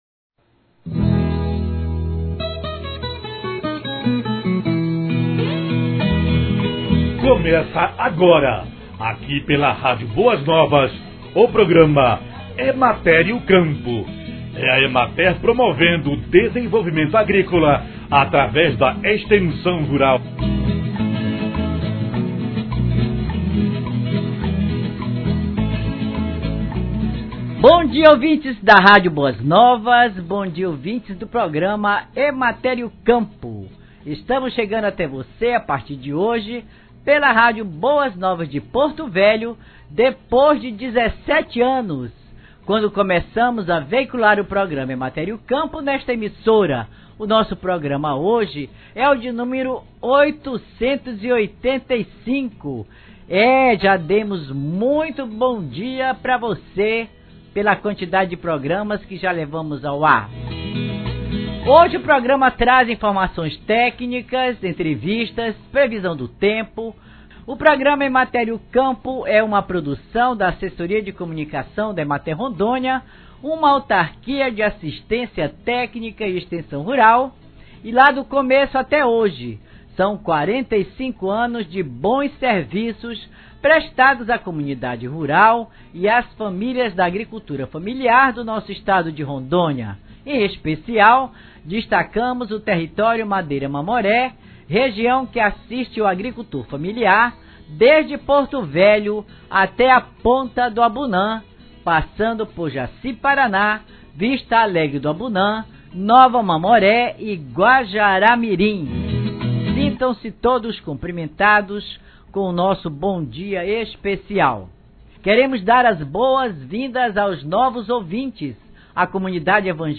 Programa de Rádio